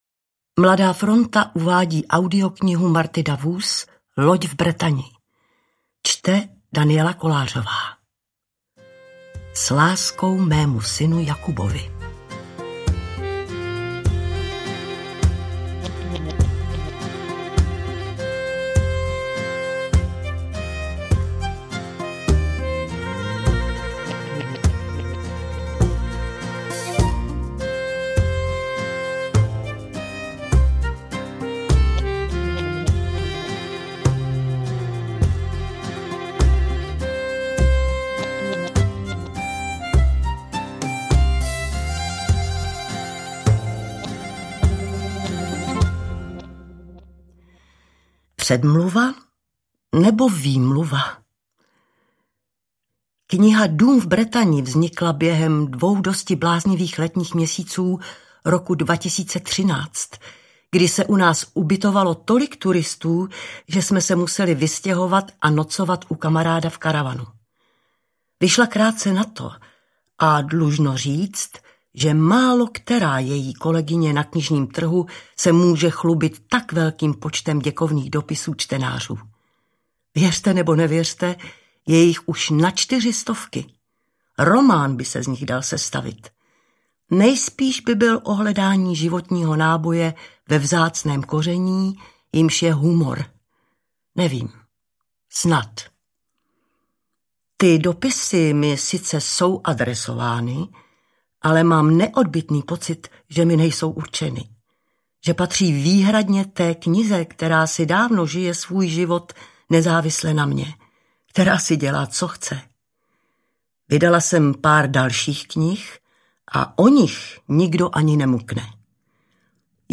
Interpret:  Daniela Kolářová
Poslouchala jsem jako audioknihu interpretovanou Danielou Kolářovou.
AudioKniha ke stažení, 36 x mp3, délka 6 hod. 50 min., velikost 374,9 MB, česky